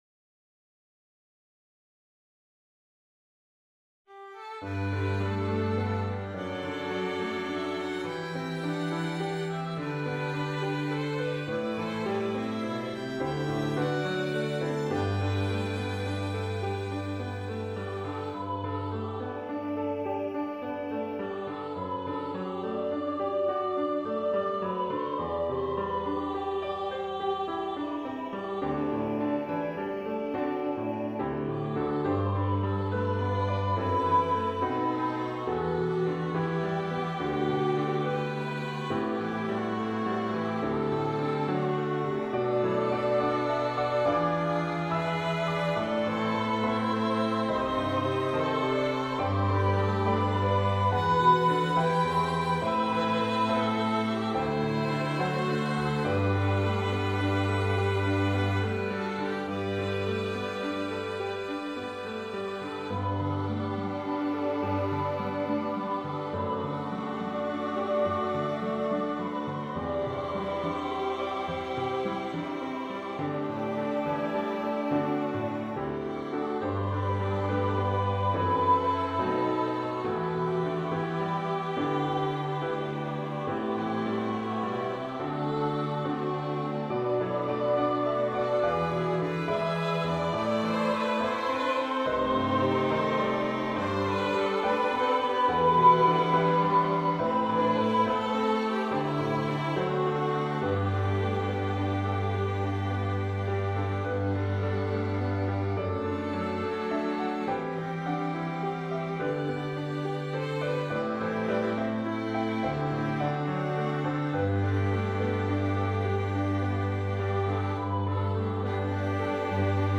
Камерный